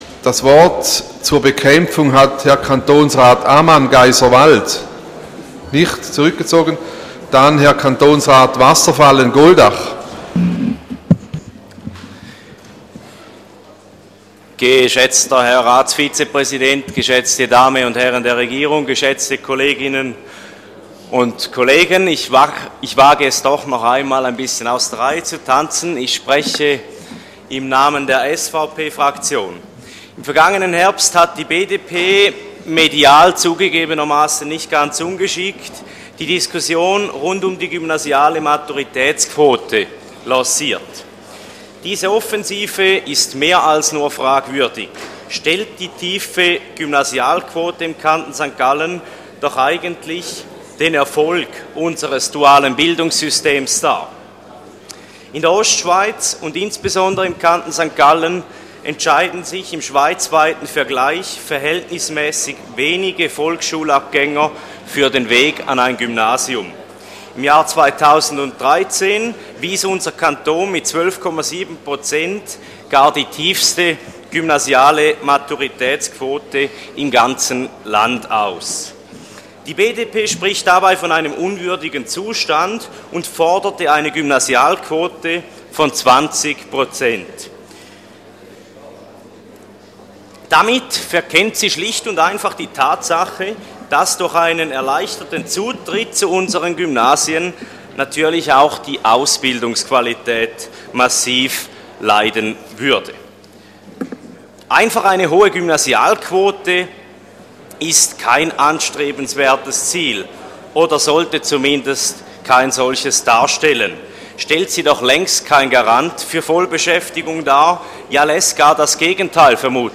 24.2.2015Wortmeldung
Session des Kantonsrates vom 23. bis 25. Februar 2015